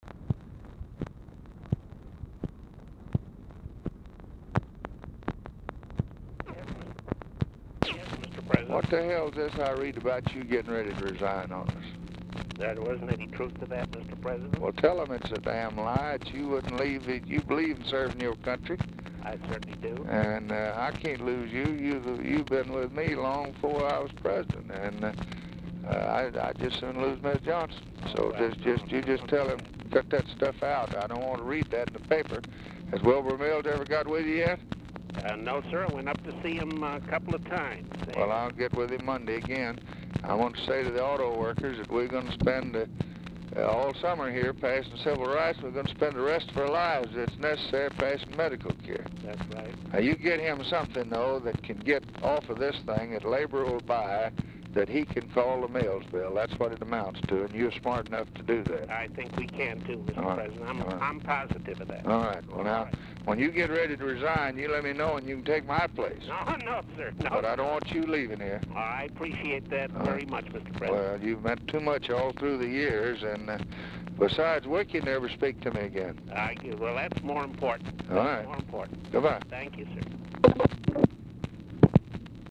Telephone conversation # 2612, sound recording, LBJ and WILBUR COHEN, 3/21/1964, 6:04PM
Format Dictation belt
Oval Office or unknown location